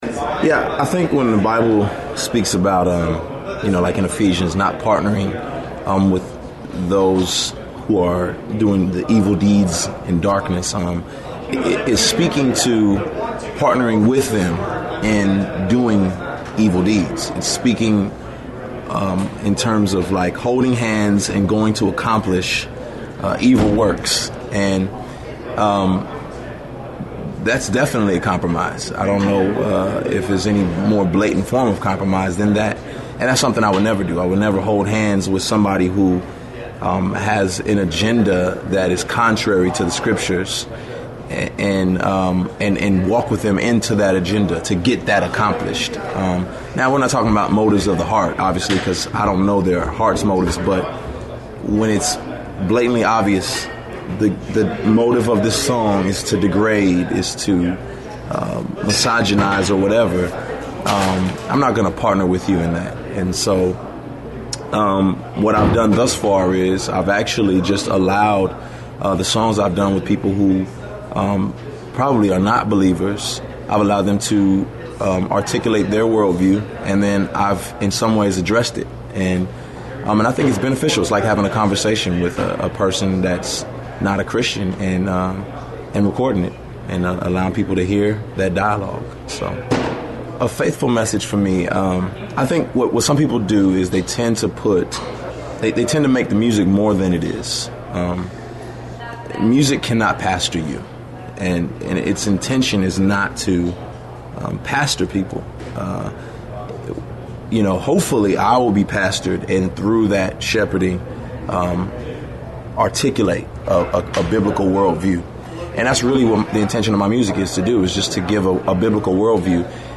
Here’s his answer (3-minute audio):